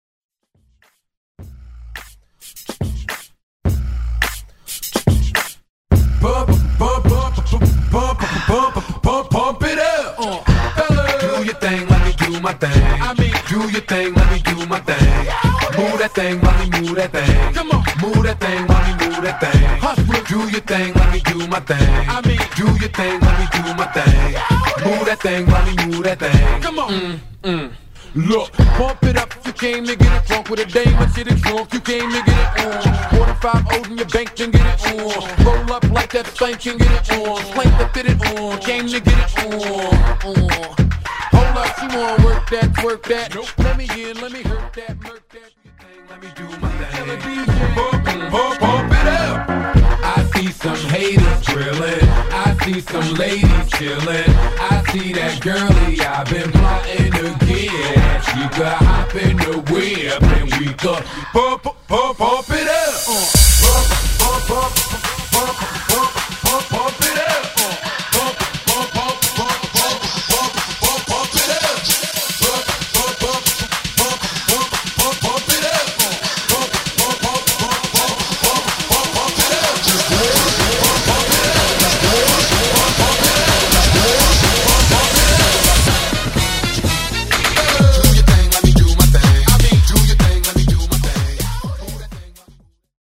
BPM: 106-125 Time